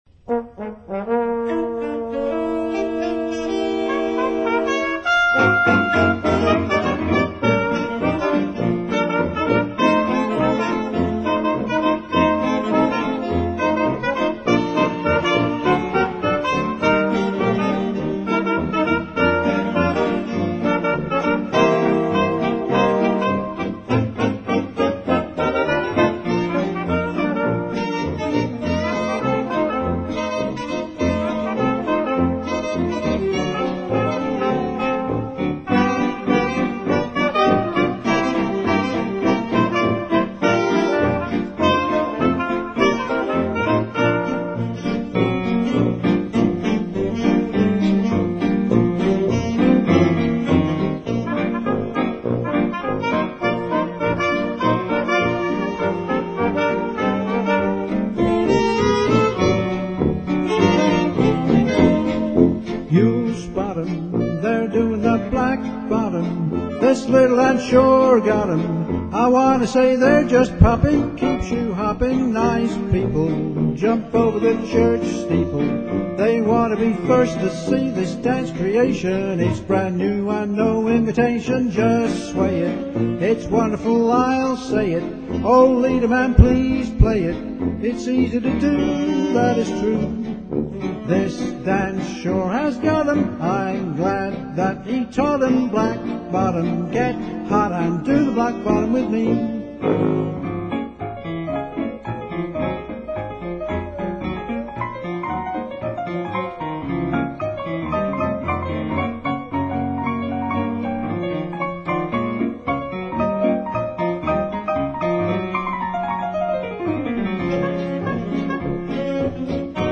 Please note quality reduced for internet streaming